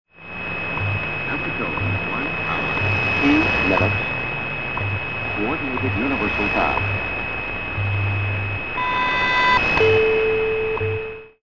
Shortwave DXing for Green River (the current active station) is done with a Grundig S350 and G8 Traveller II Digital radio set mostly to narrow bandwidth.